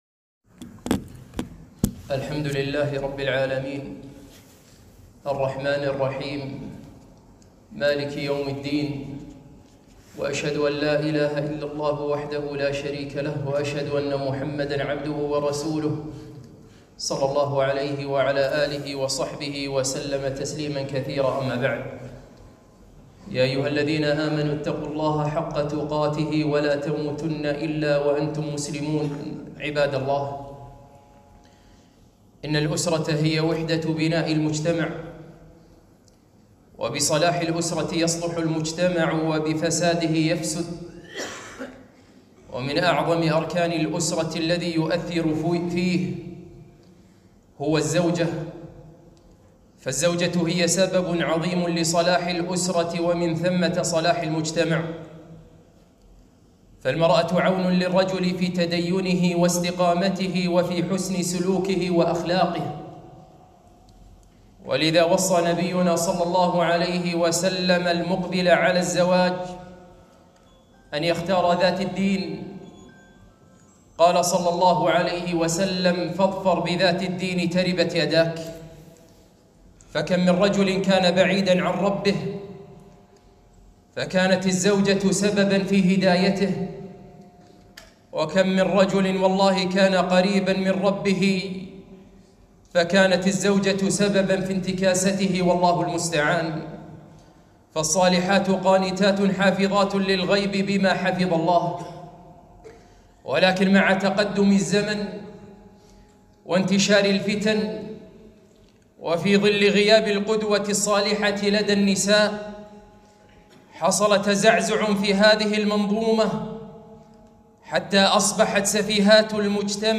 خطبة - أيها الزوجات! هؤلاء هن القدوات